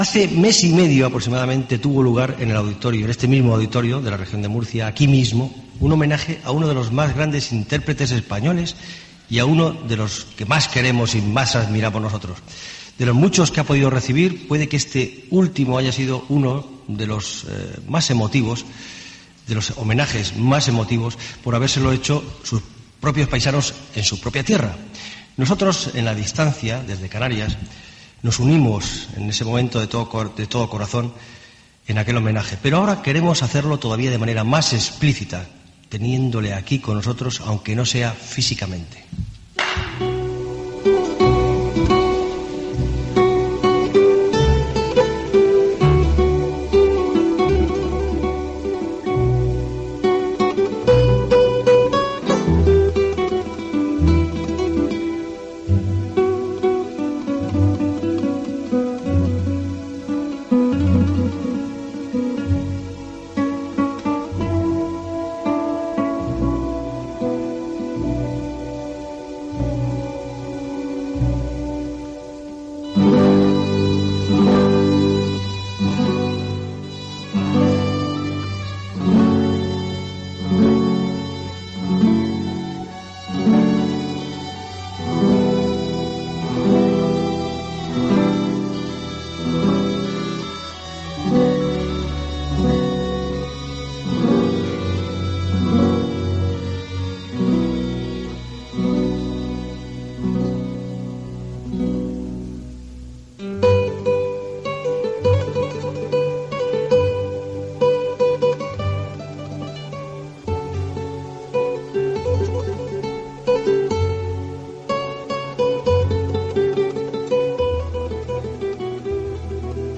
Espai fet des de Canàries amb l'última entrevista, telefònica, que es va fer al guitarrista Narciso Yepes
Musical